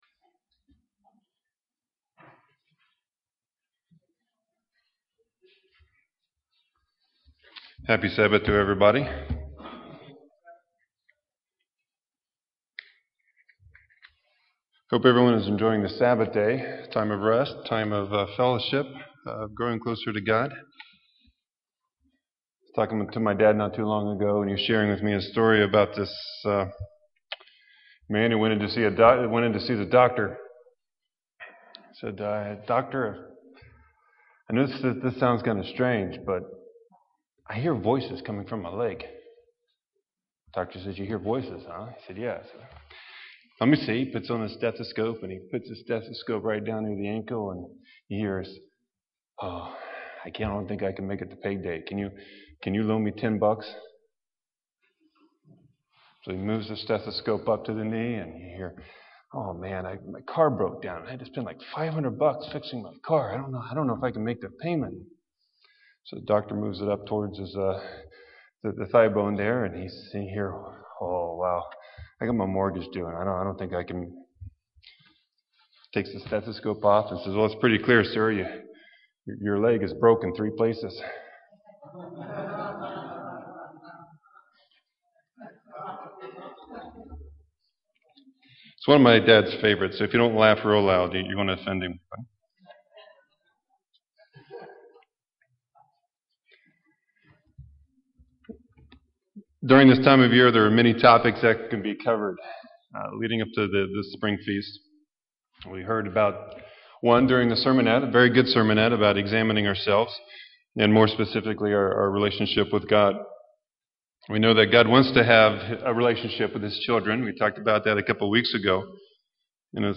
Sermon: Who Shall Dwell?